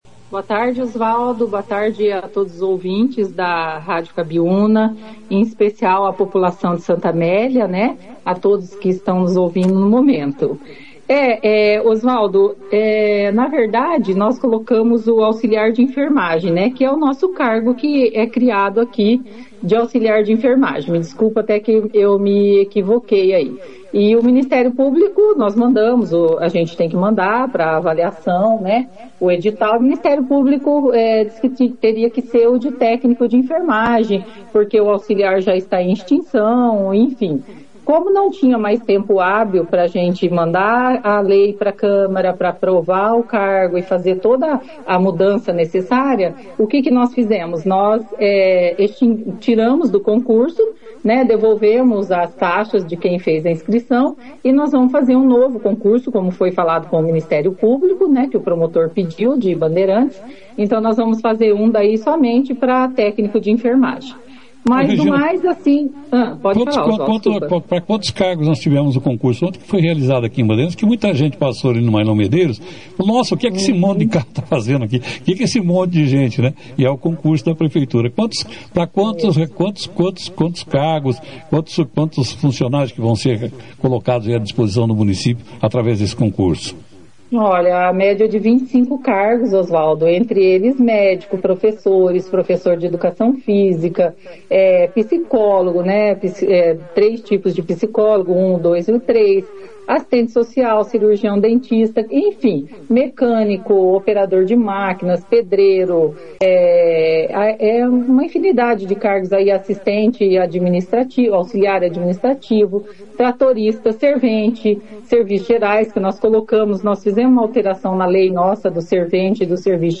A secretária municipal de Administração de Santa Amélia, Regina Fabris, (foto), participou da 2ª edição do Jornal Operação Cidade, desta segunda-feira, 05 de janeiro, quando falou sobre o concurso público realizado ontem pelo município, esclarecendo dúvidas importantes, especialmente relacionadas ao cargo na área da enfermagem.